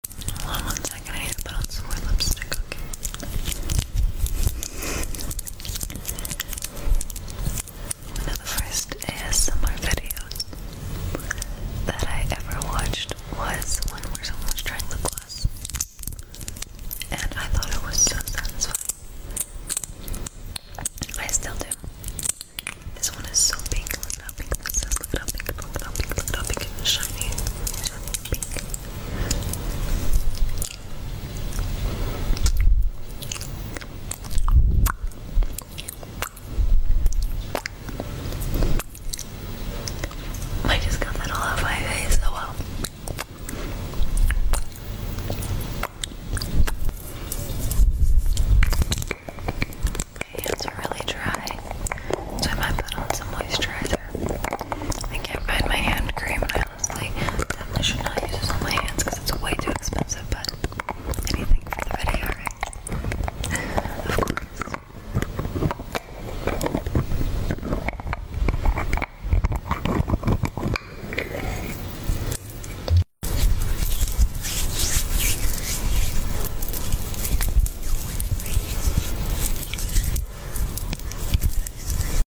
Her Voice Is So Smoothing Sound Effects Free Download